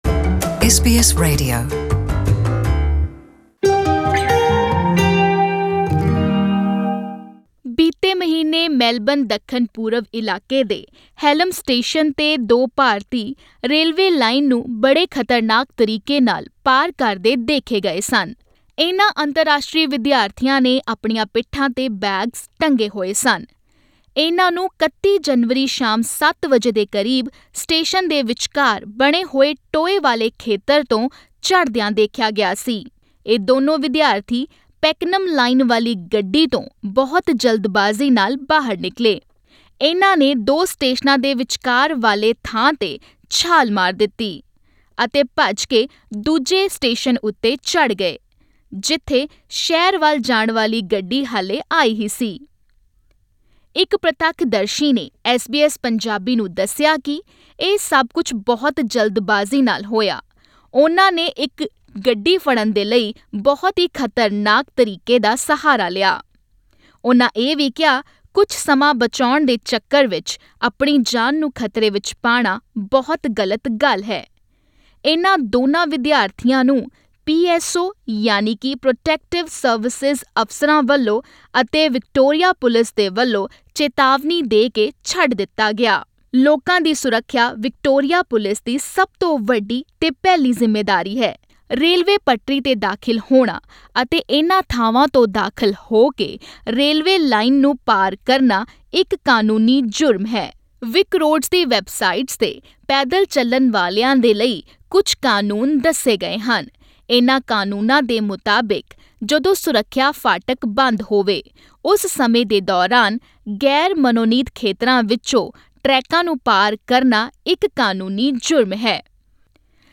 ਪੇਸ਼ ਹੈ ਇਸ ਬਾਰੇ ਇੱਕ ਖਾਸ ਰਿਪੋਰਟ...